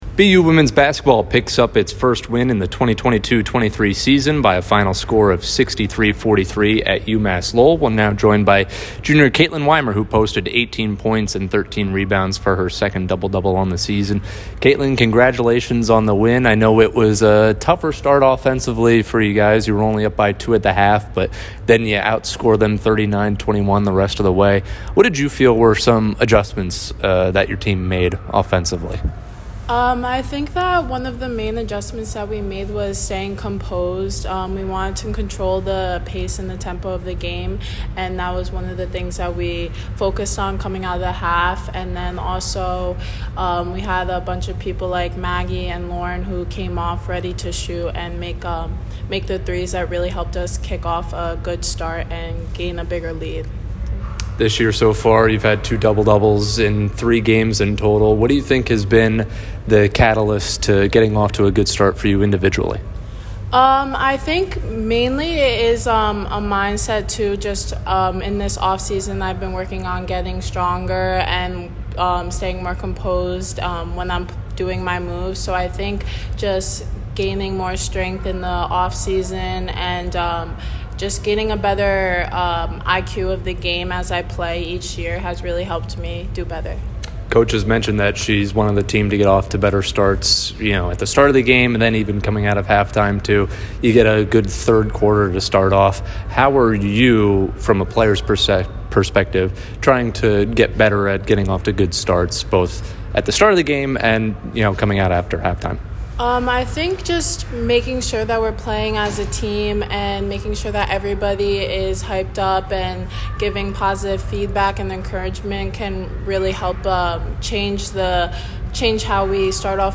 WBB_Lowell_Postgame.mp3